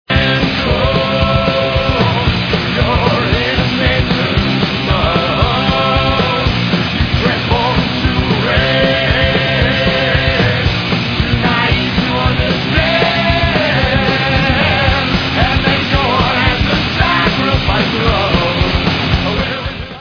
Rock/Hardcore